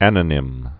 (ănə-nĭm)